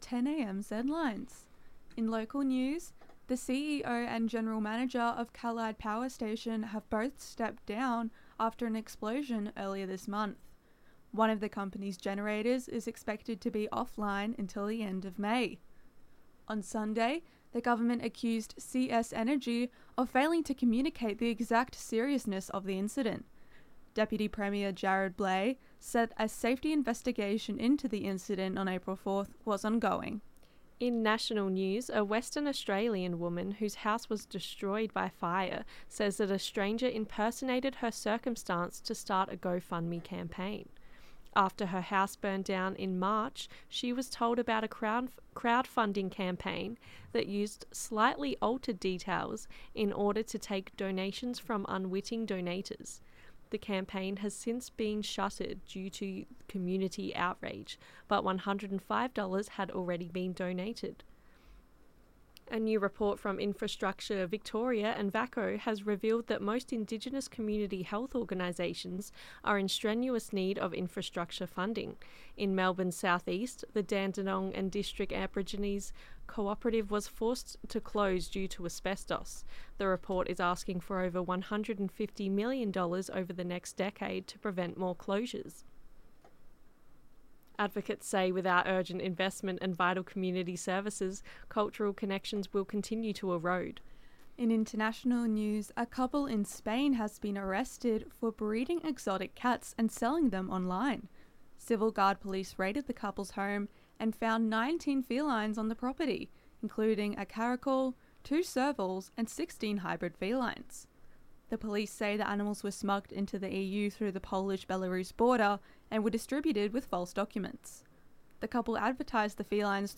Power Station (Pixabay/Pexels under CC BY-ND 2.0) Zedlines Bulletin 10AM ZEDLINES 15.4.25.mp3 (2.67 MB)